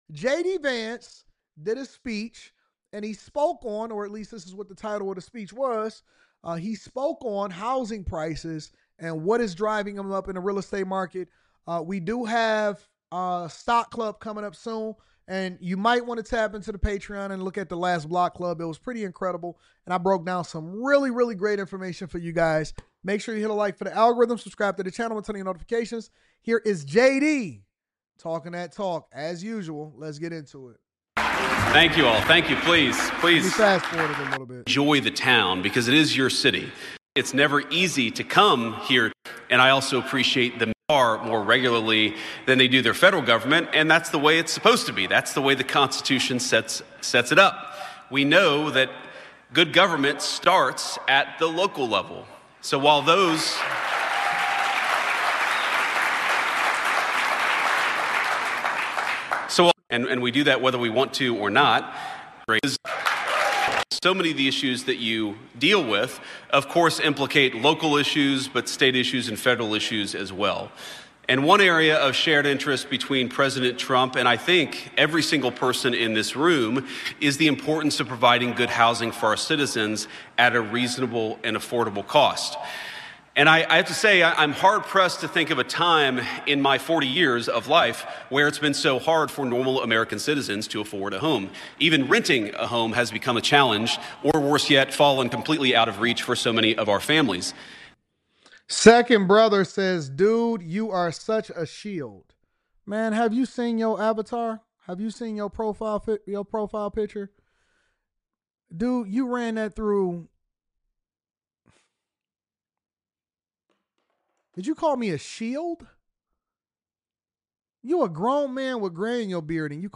Illegal Migrants Cause Shortage In Housing... JD Vance Heckled During Speech About Real Estate